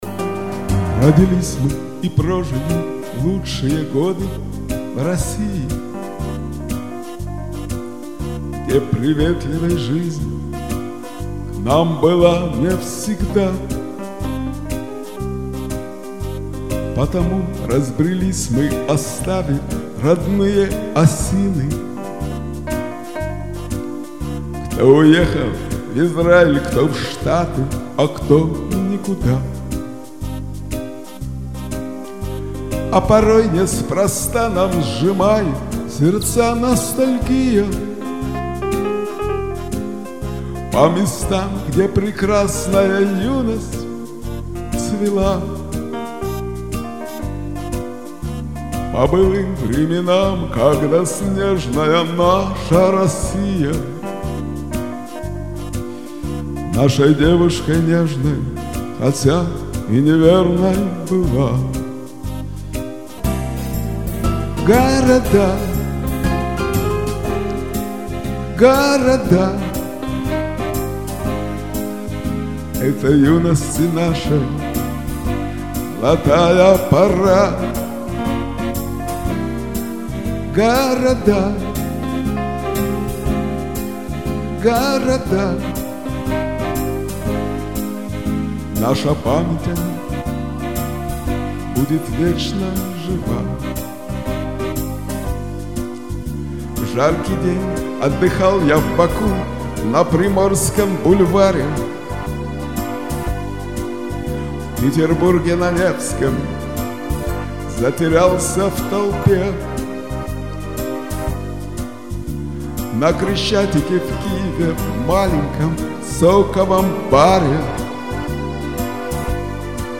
Его композиции очень мелодичны, слова трогают душевные струны.
Действительно,приятное исполнение.Не претендую на просвещенность, но песенка приятная.